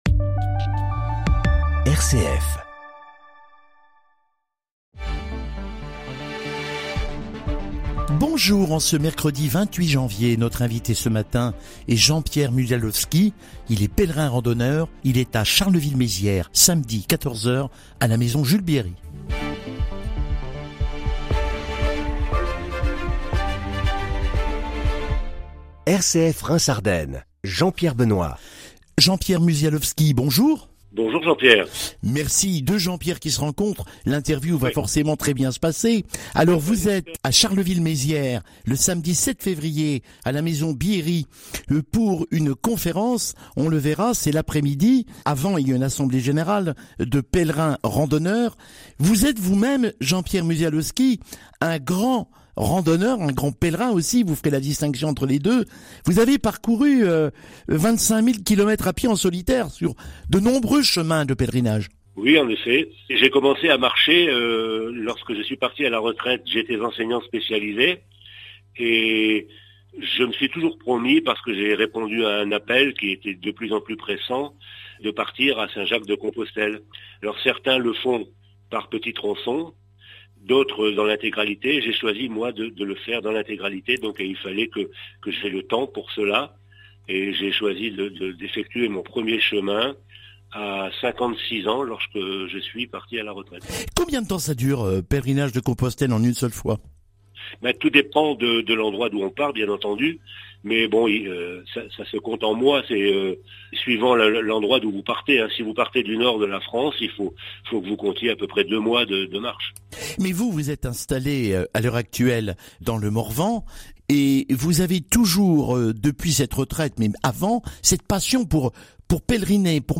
Interview Reims.mp3